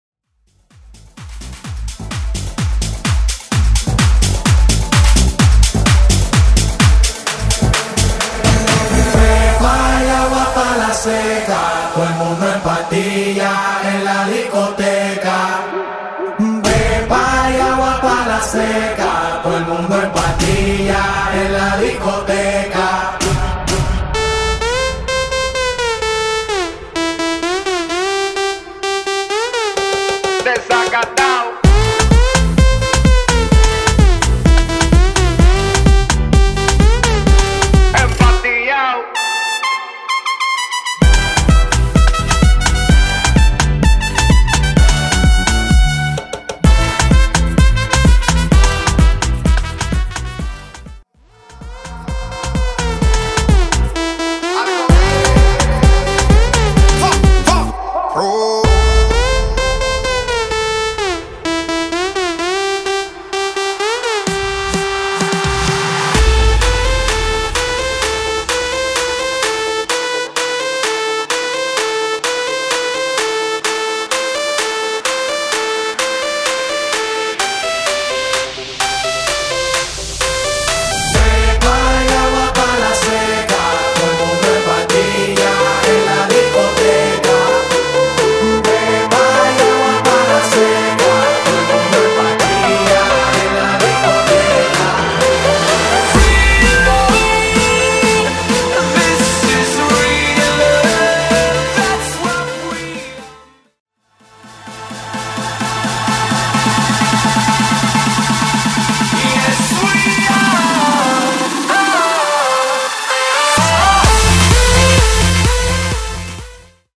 Genres: BOOTLEG , EDM , TOP40
Clean BPM: 128 Time